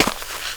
Index of /90_sSampleCDs/AKAI S6000 CD-ROM - Volume 6/Human/FOOTSTEPS_2
SNEAK DIRT 1.WAV